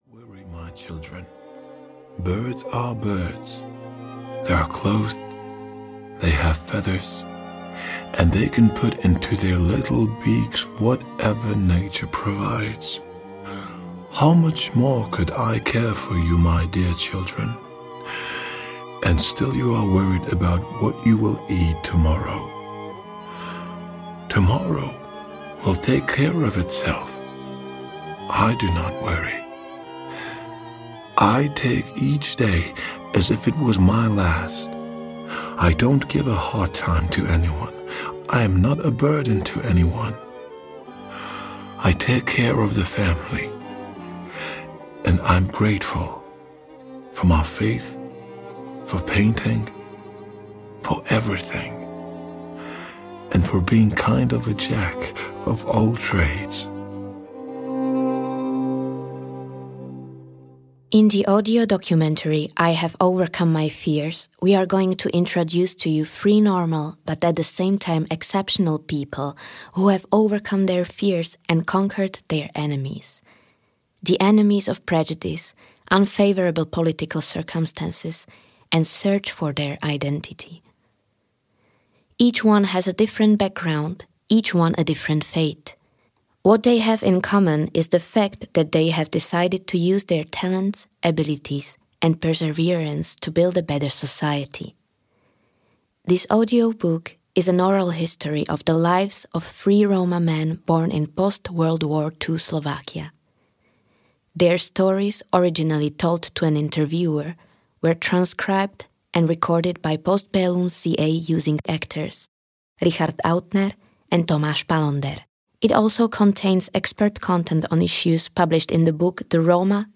I have overcome my fears audiokniha
Ukázka z knihy